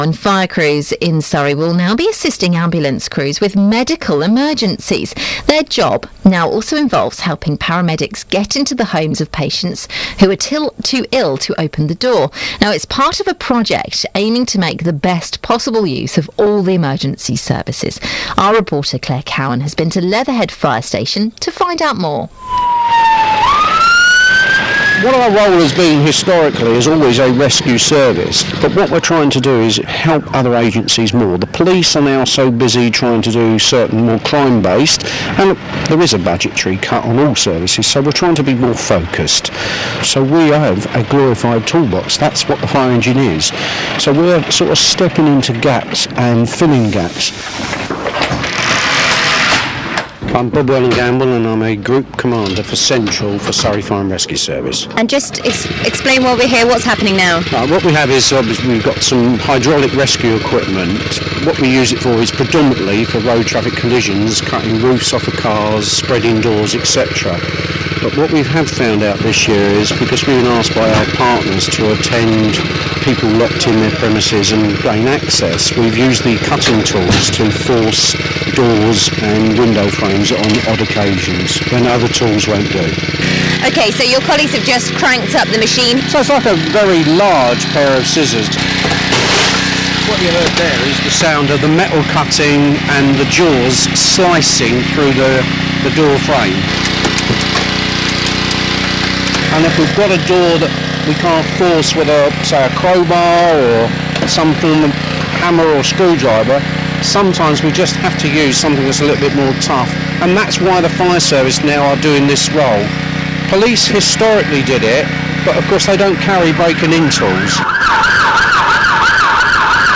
Audio courtesy of BBC Surrey.